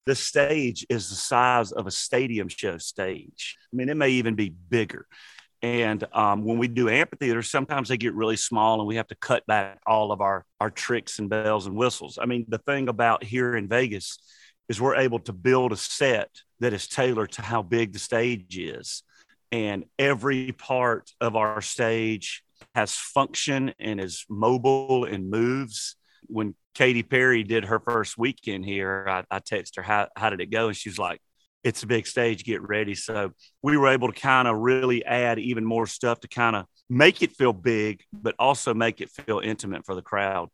Audio / Luke Bryan explains how his set for Las Vegas is different from the set when he’s on tour.